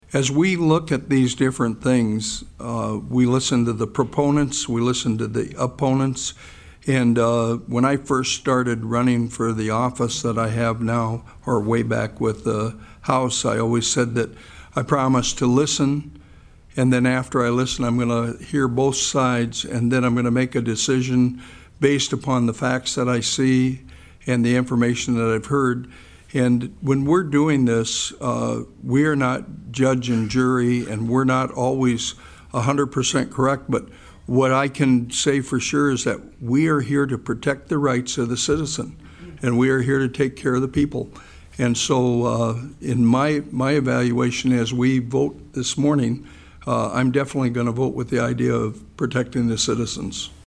PIERRE, S.D.(HubCityRadio)- The South Dakota Senate State Affairs Committee heard testimony on SB49.
Among the committee members, Senator Carl Perry expressed the most support to the bill.